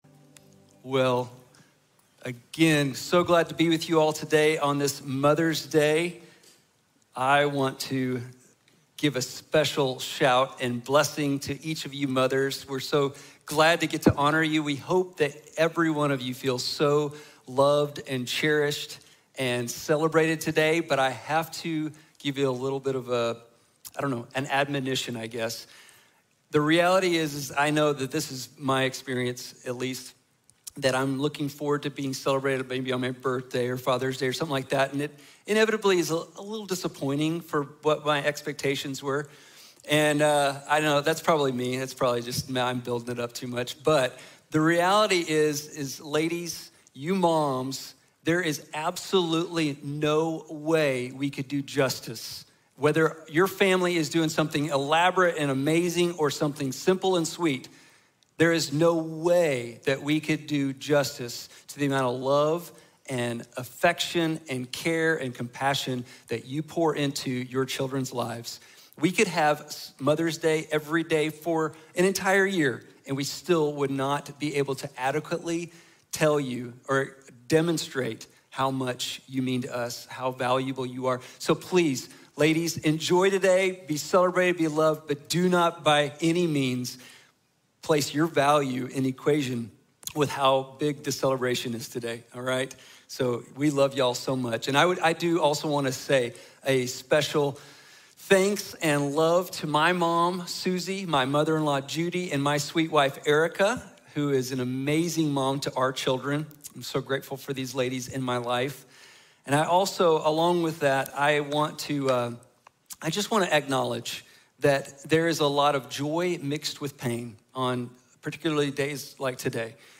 Hagar | Sermon | Grace Bible Church